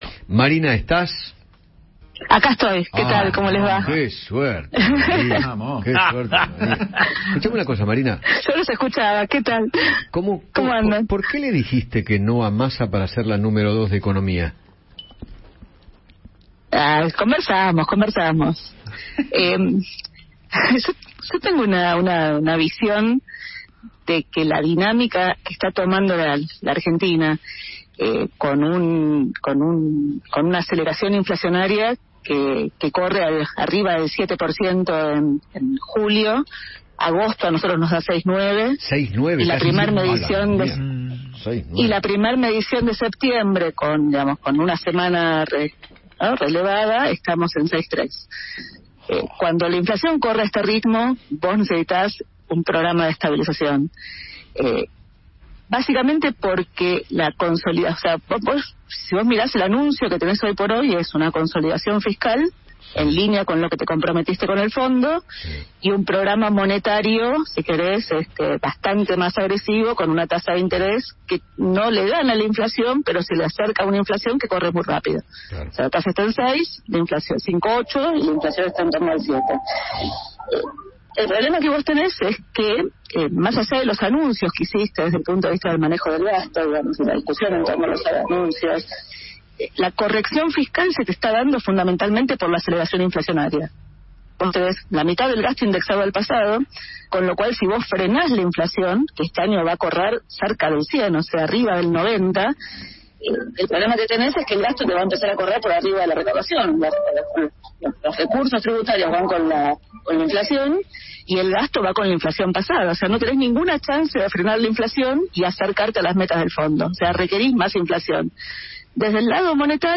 La economista Marina Dal Poggetto dialogó con Eduardo Feinmann sobre la inflación que se espera del mes de agosto y analizó el IPC de la primera semana de septiembre.